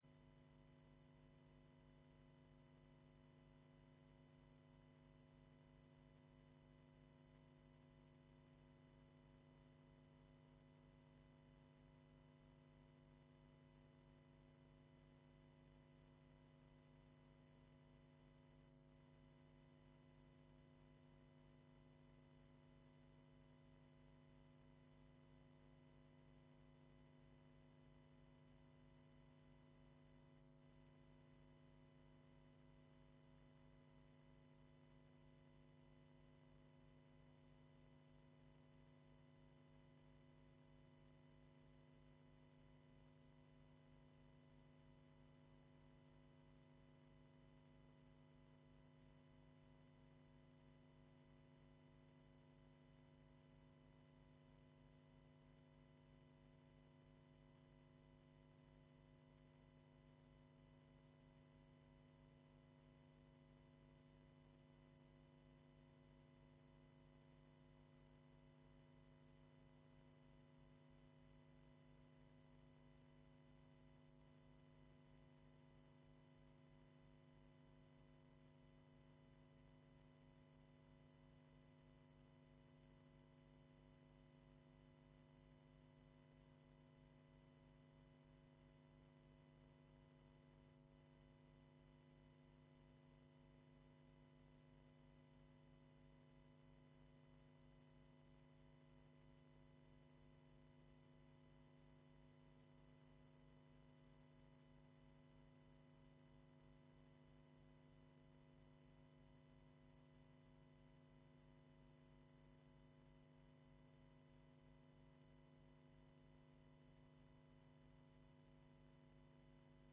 �udio de la sessi� plen�nia/Audio de la sessi�n plenaria